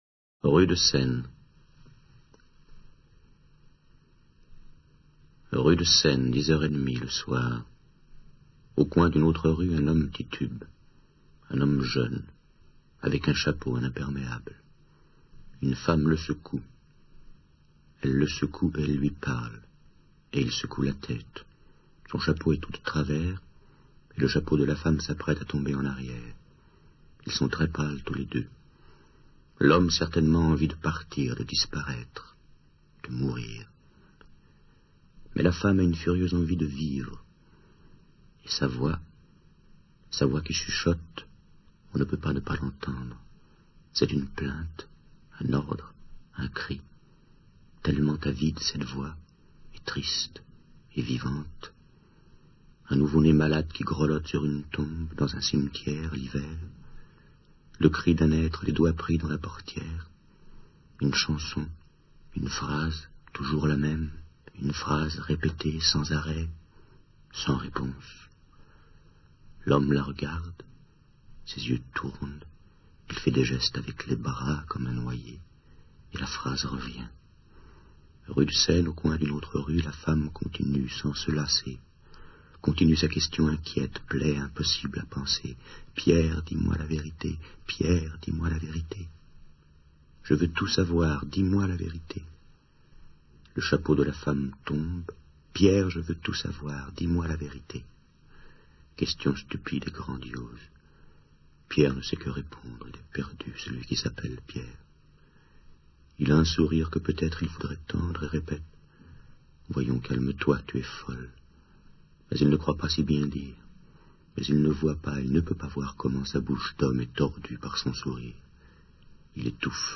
dit par Serge REGGIANI